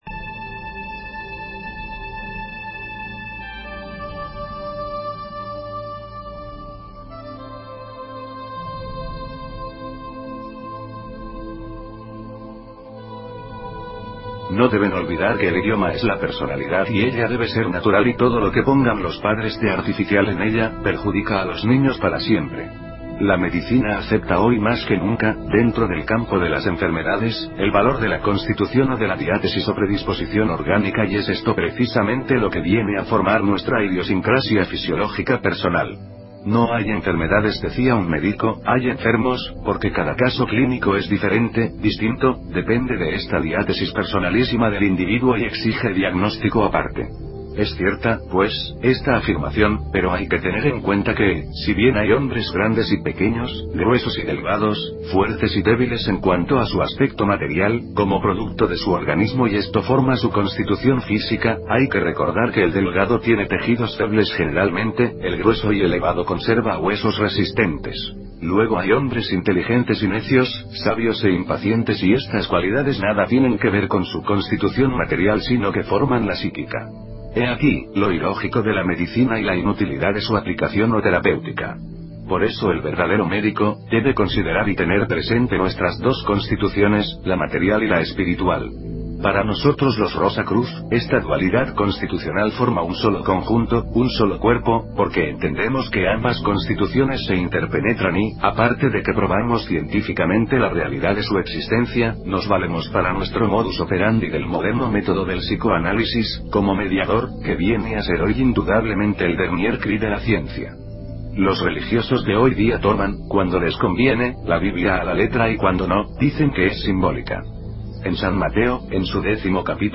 Audio Libro